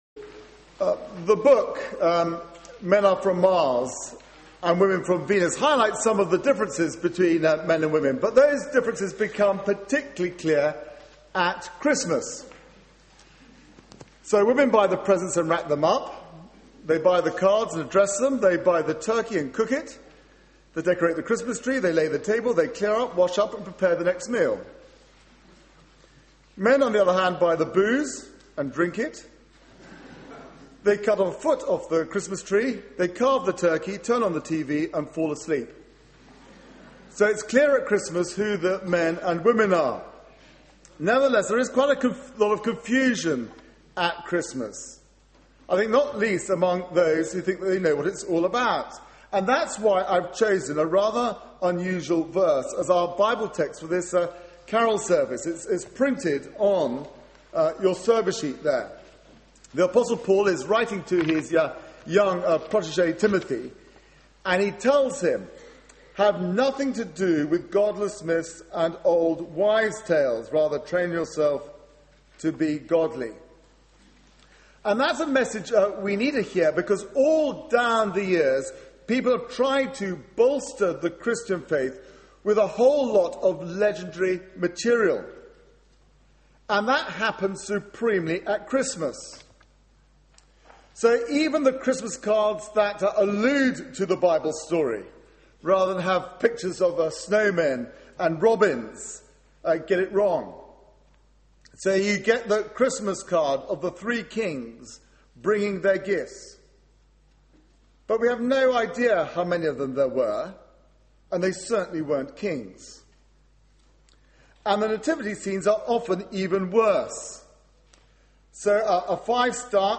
Media for 6:30pm Service on Sun 18th Dec 2011 18:30 Speaker
Part 4: Sermon Search the media library There are recordings here going back several years.